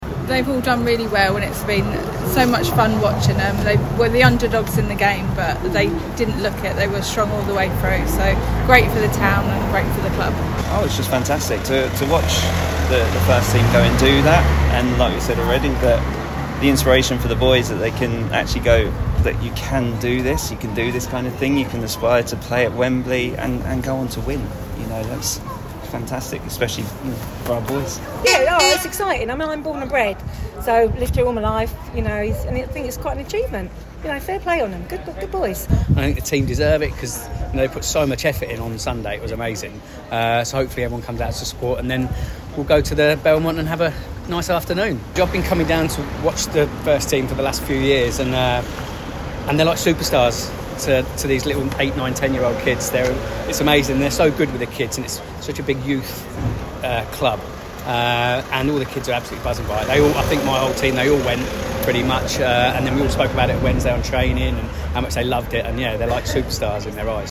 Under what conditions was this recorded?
Listen: fans have lined the streets for an open-top bus parade to celebrate Whitstable Town winning the FA Vase - 17/05/2025